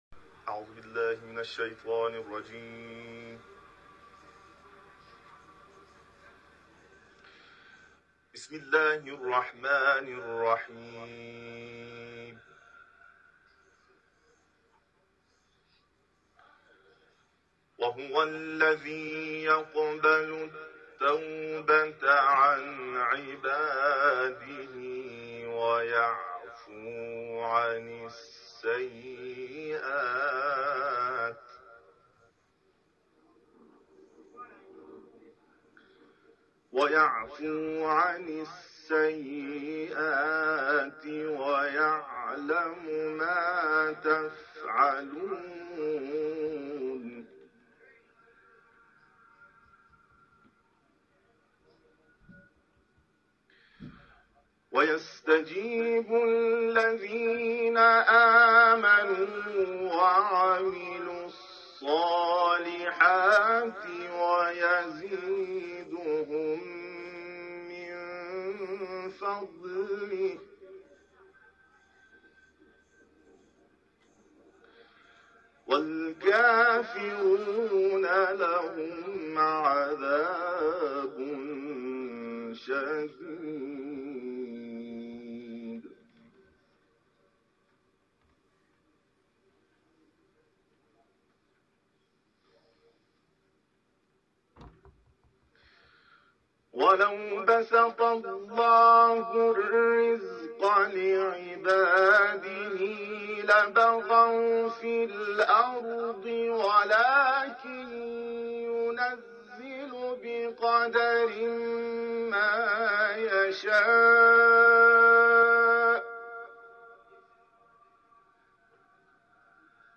صوت این تلاوت که مضامینی همچون توبه‌پذیری خداوند و قدرت پروردگار را شامل می‌شود، در قسمت زیر قابل پخش است.